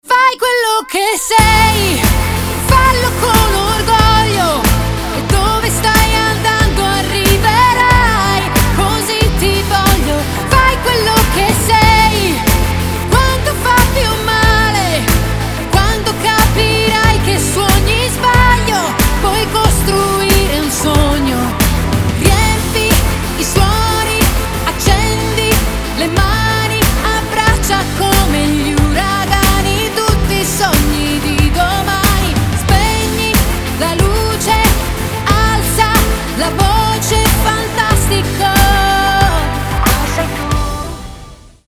• Качество: 320, Stereo
поп
Pop Rock